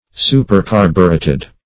Search Result for " supercarbureted" : The Collaborative International Dictionary of English v.0.48: Supercarbureted \Su`per*car"bu*ret`ed\, a. (Chem.)
supercarbureted.mp3